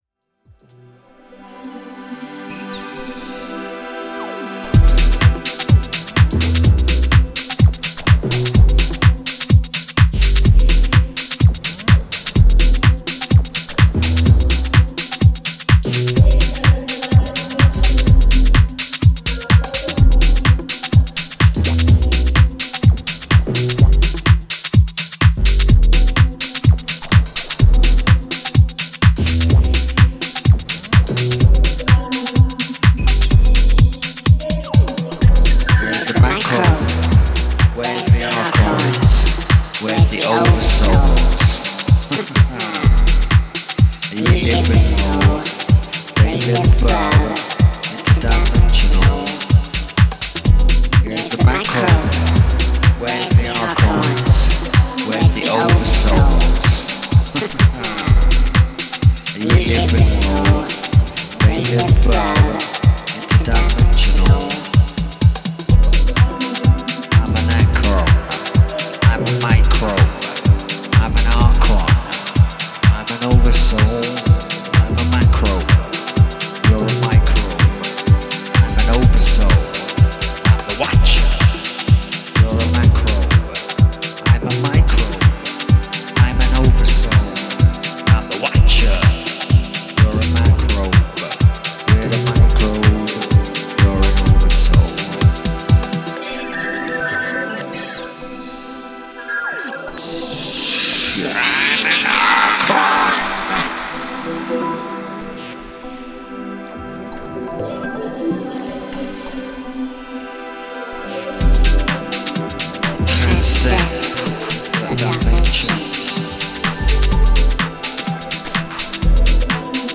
フリーキーなヴォイスサンプリングが多用されたチャンキーなグルーヴ